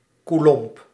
Ääntäminen
IPA: [kuˈlɔ̃]